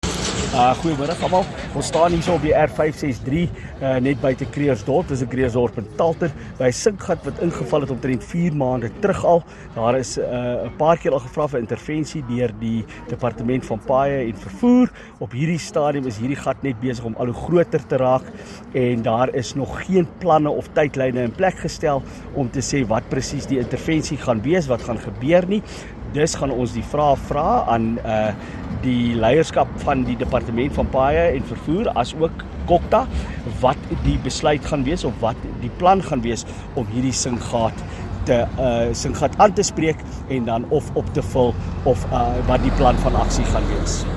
Afrikaans soundbites by Evert Du Plessis MPL